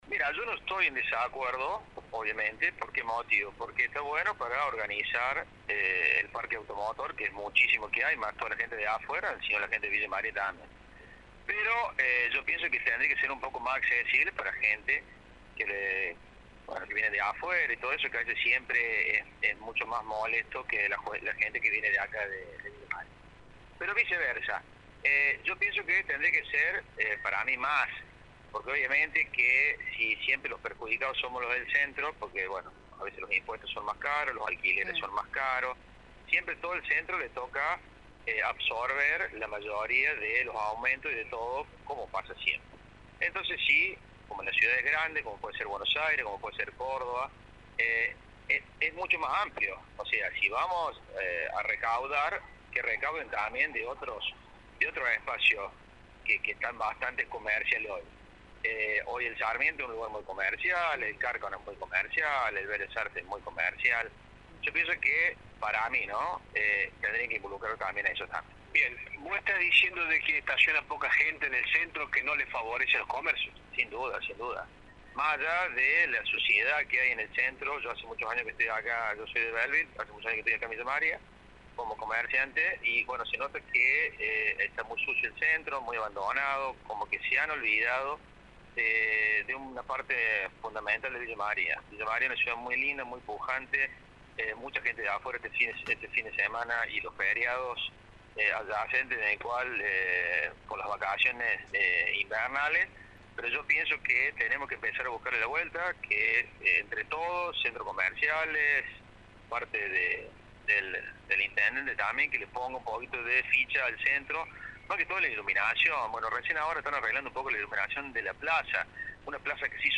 dialogó con nuestro medio y brindó detalles de por qué se reúnen.